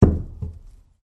国内的声音 " 大块的木头被扔下03
描述：在一个水泥地板上下落的大槭树日志 用数字录音机录制并使用Audacity处理